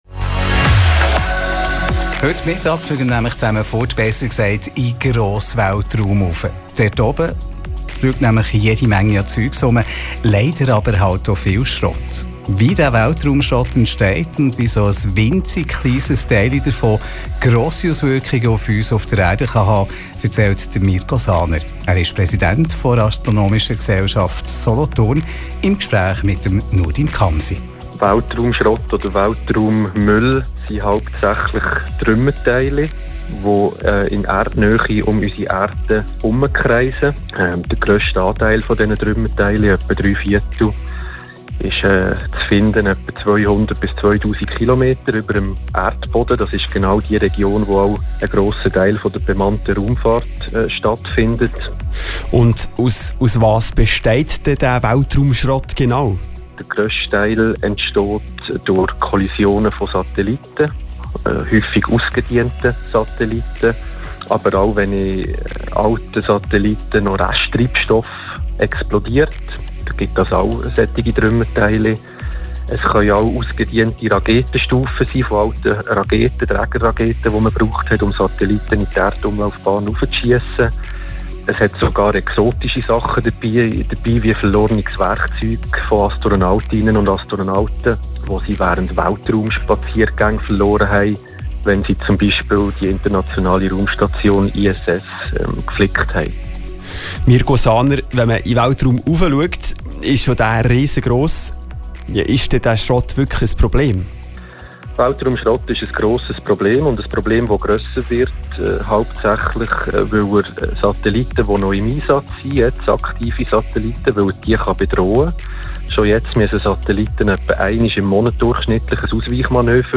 Interview Radio 32 Okt. 2021zum Thema Weltraumschrott 1